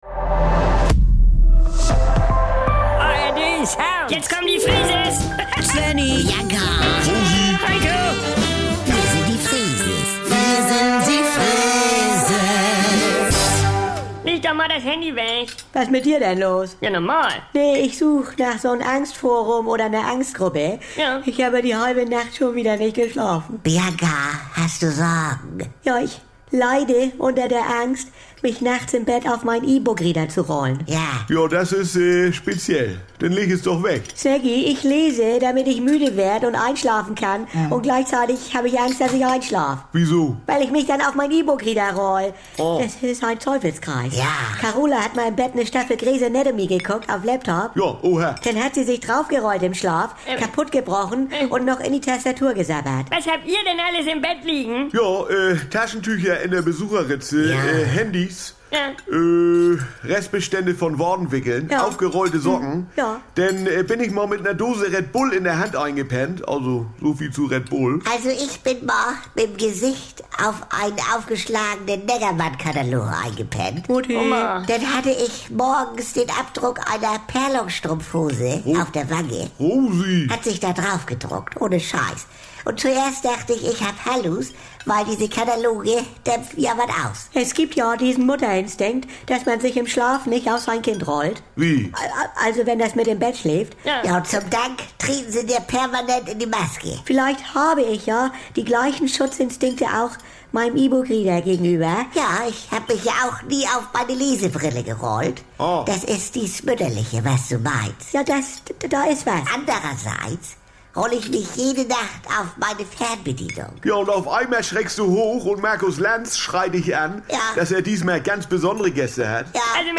Jederzeit und so oft ihr wollt: Die NDR 2 Kult-Comedy direkt aus